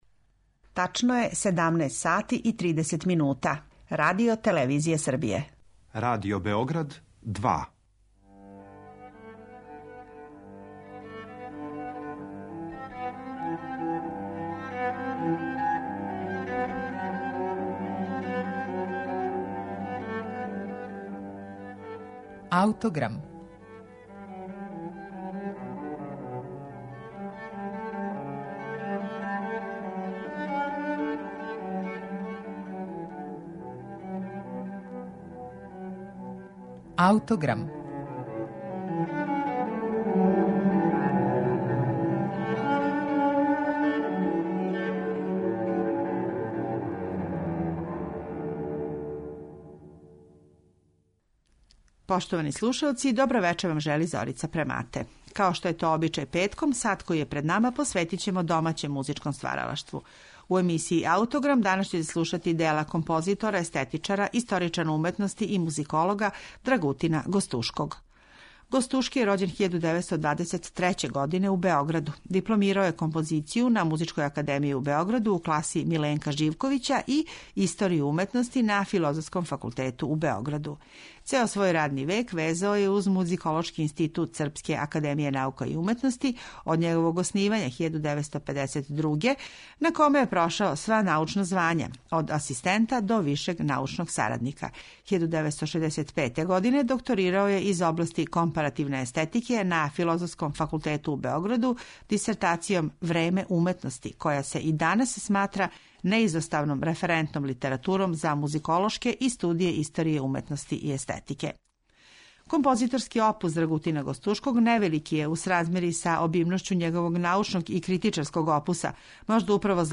са архивског снимка